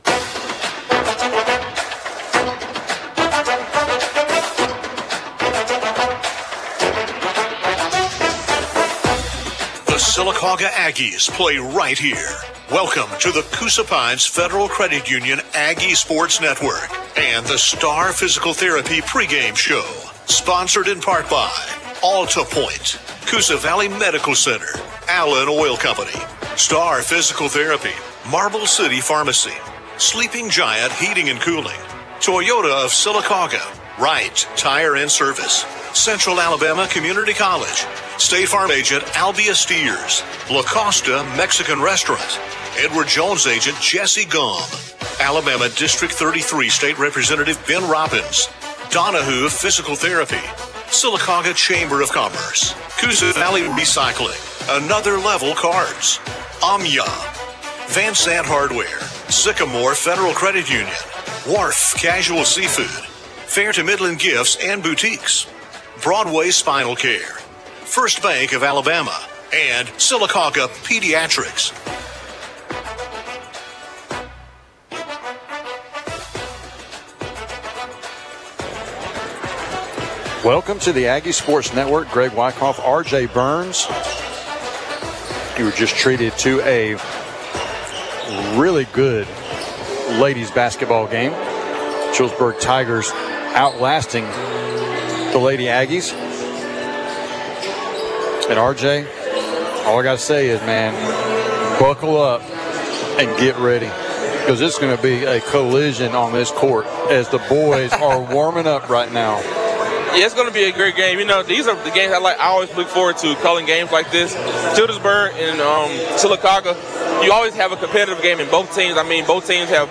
(Boys Basketball) Sylacauga vs. Childersburg